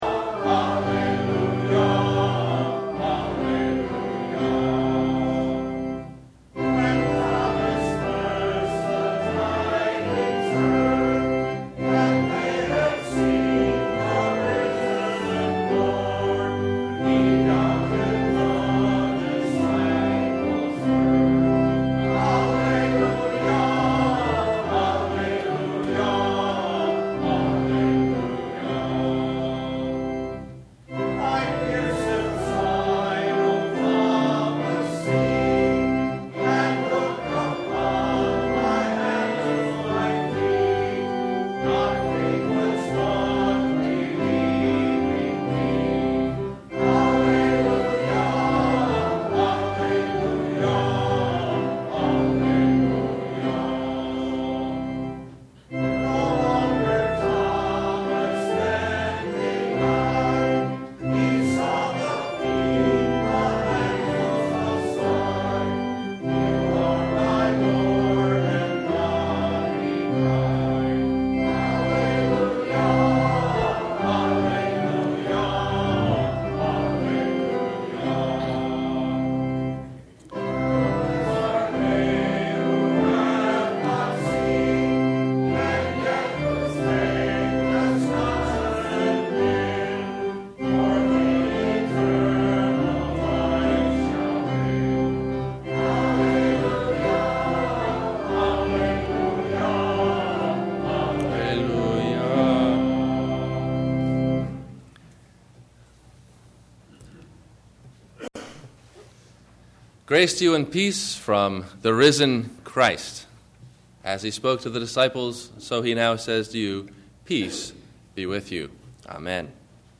The Encounter, Resurrection of body, soul and spirit – Sermon – March 30 2008